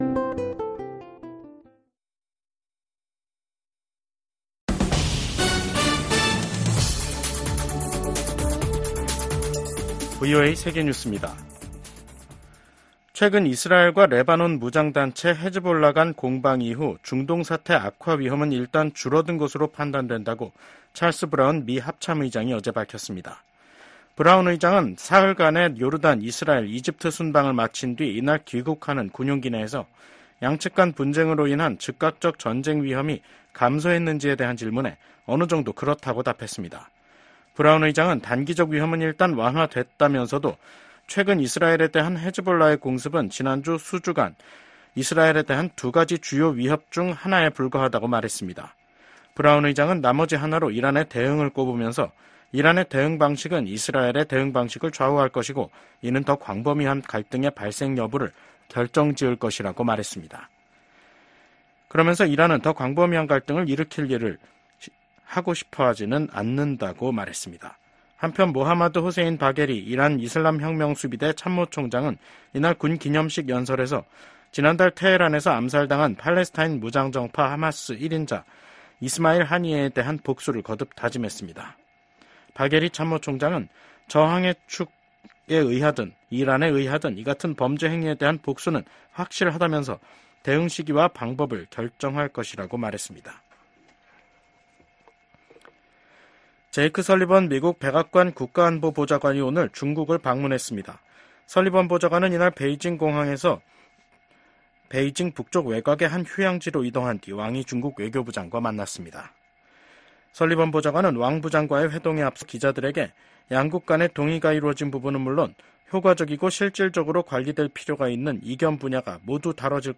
VOA 한국어 간판 뉴스 프로그램 '뉴스 투데이', 2024년 8월 27일 2부 방송입니다. 북한이 영변 핵 시설을 가동하고 평양 인근 핵 시설인 강선 단지를 확장하고 있다고 국제원자력기구(IAEA)가 밝혔습니다. 미국 정부가 한국에 대한 아파치 헬기 판매가 안보 불안정을 증대시킬 것이라는 북한의 주장을 일축했습니다. 북한이 새 ‘자폭용 무인기’를 공개한 가운데 미국의 전문가들은 미사일보다 저렴한 비용으로 한국의 방공망을 위협할 수 있다고 우려했습니다.